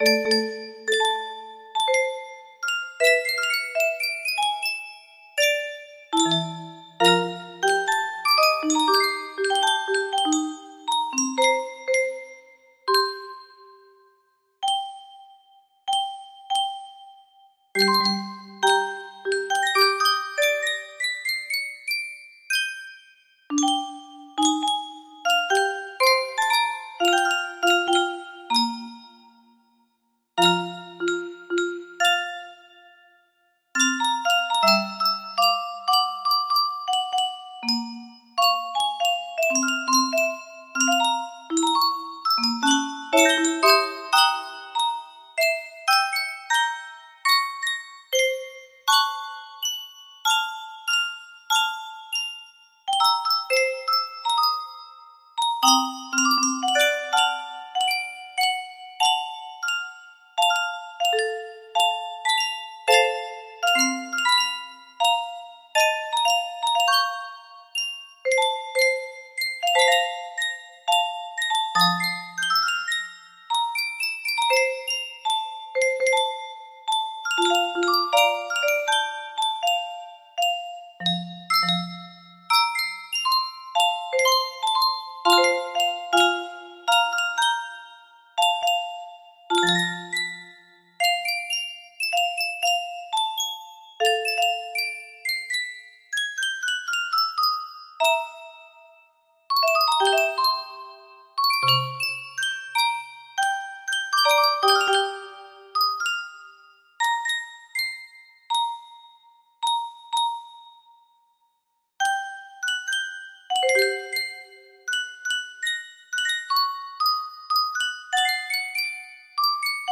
Full range 60
(᨟ ͜● ᨟) (Full Range and No Reds) (᨟ ͜● ᨟)
Key of C